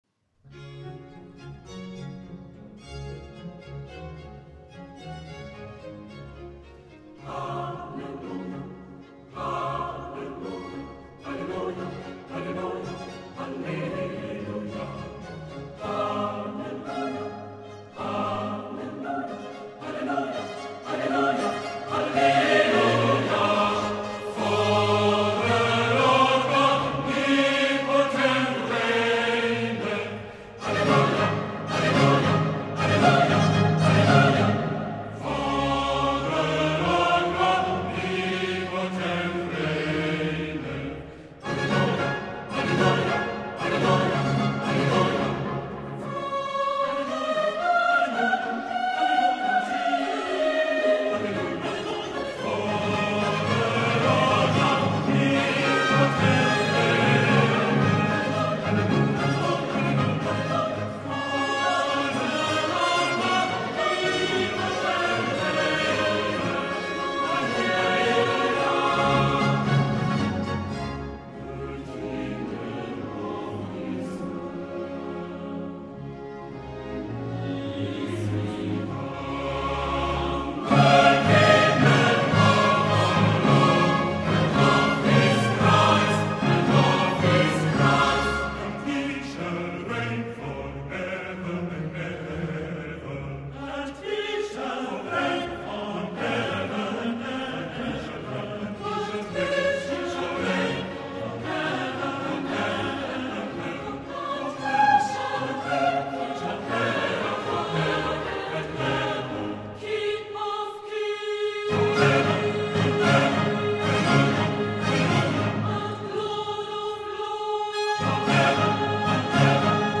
Er legt vor allem Wert darauf, so viel wie möglich von der ganz besonderen Aura der Konzerte zu vermitteln, was ausgezeichnet gelingt.
Air from Messiah ~ CD II, No. 13
(Bass)